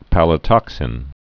(pălə-tŏksĭn)